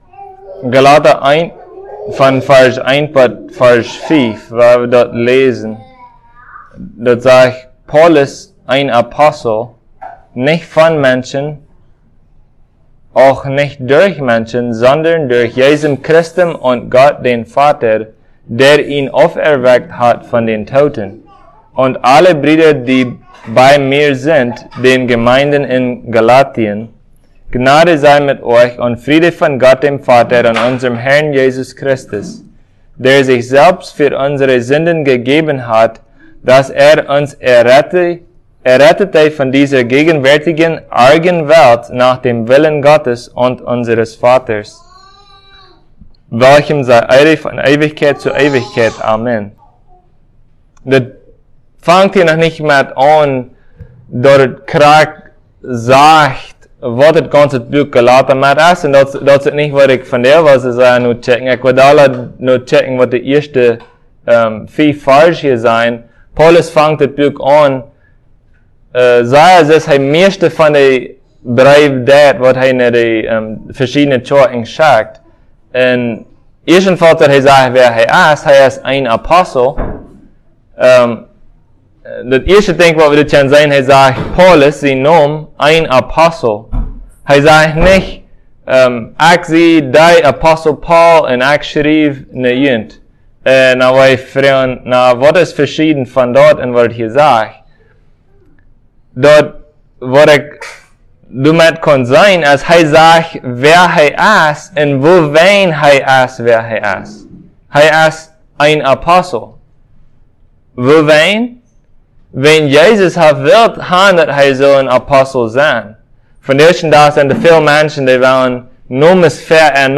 Passage: Galatians 1:1-5 Service Type: Sunday Plautdietsch